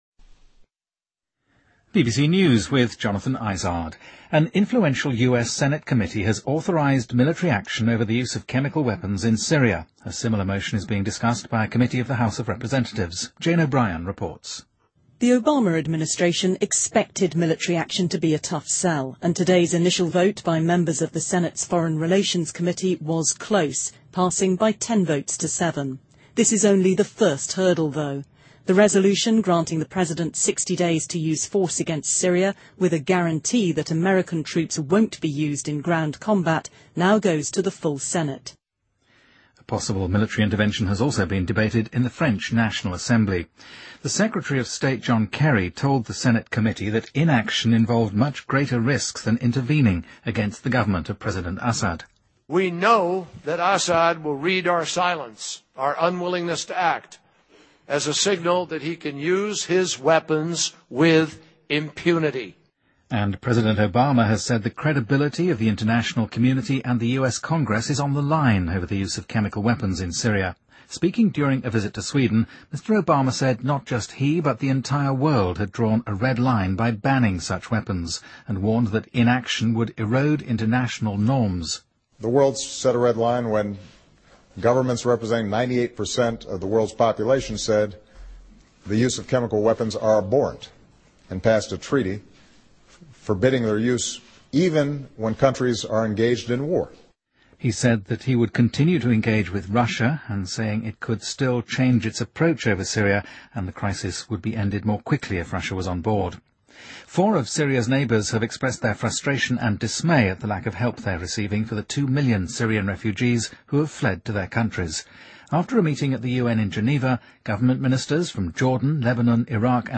BBC news,国有影响力的参议院委员会授权就化学武器使用对叙利亚动武